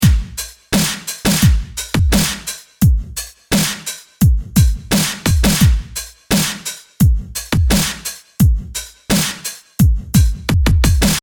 Bitcrushed Drum And Bass Drums180BPM
描述：碎裂的鼓和低音鼓
Tag: 180 bpm Drum And Bass Loops Drum Loops 921.31 KB wav Key : Unknown